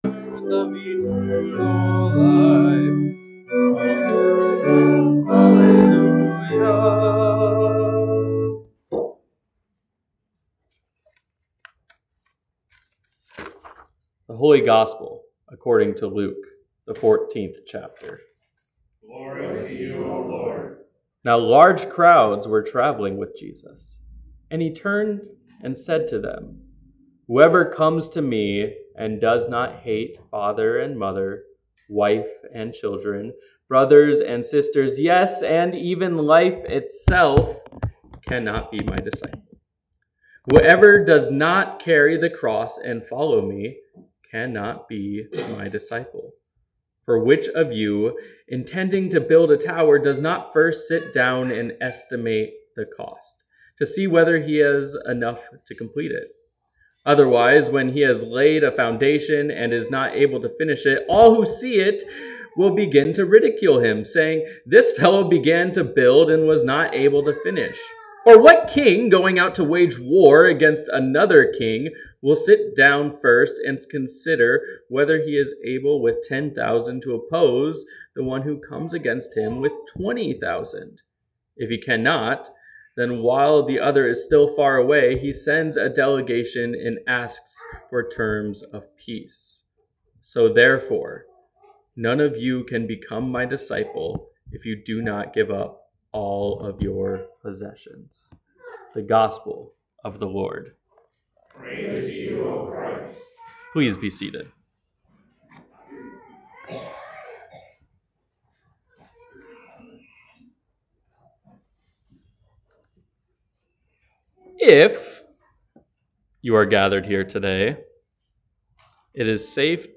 Sermons | Bethany Lutheran Church